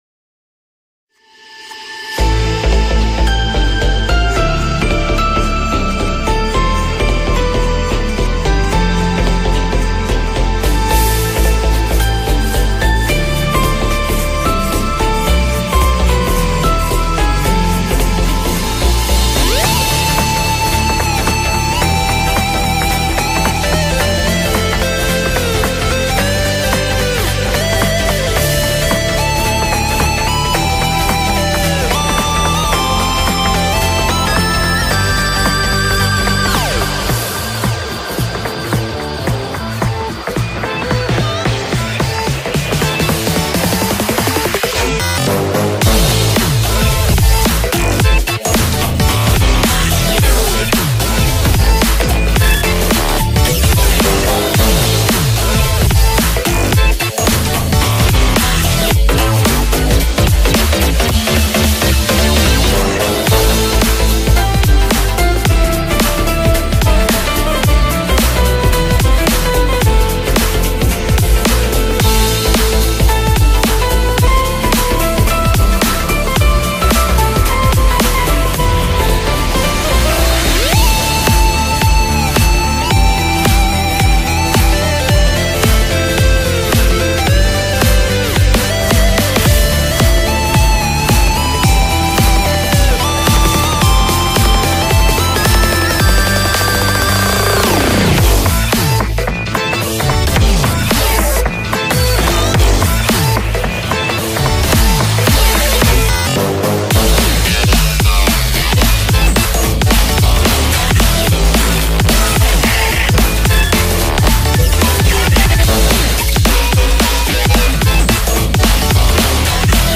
BPM110
Audio QualityCut From Video